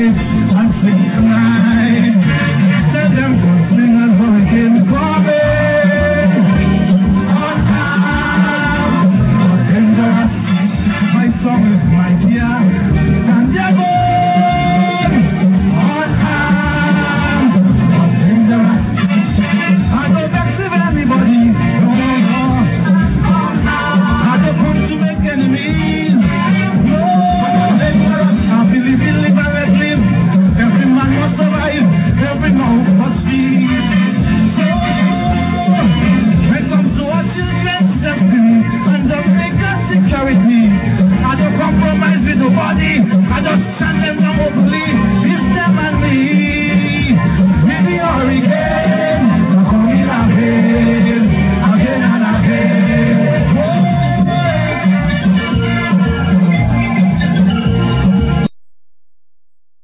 Click on these MEMORABLE CAISO SNIPETS(soon on DVD)